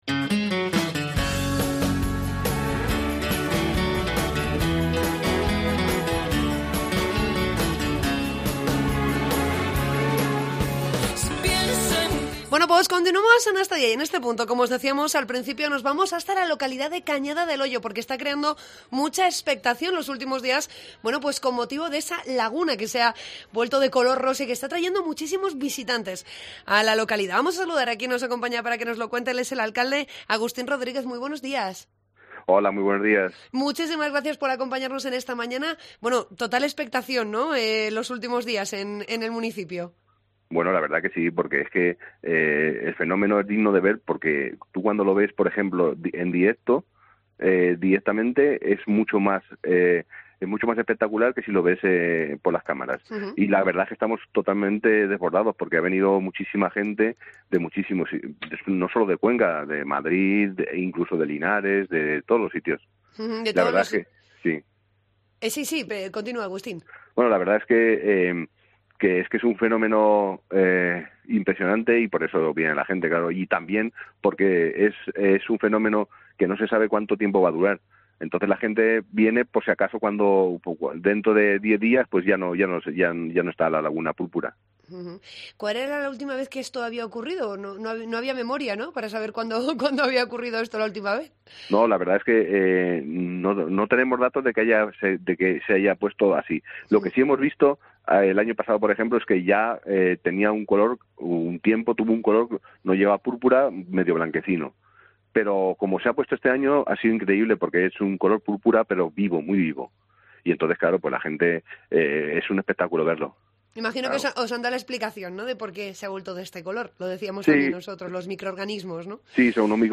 Entrevista con el alcalde de Cañada del Hoyo, Agustín Rodríguez